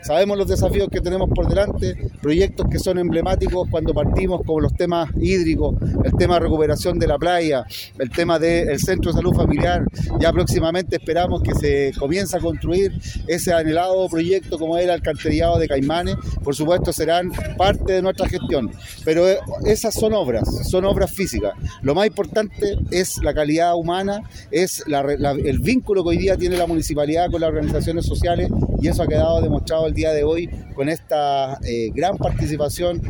Frente al edificio consistorial se desarrolló el acto cívico y desfile del aniversario de Los Vilos.